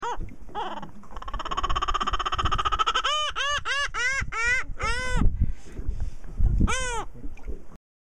laughing-seagull.mp3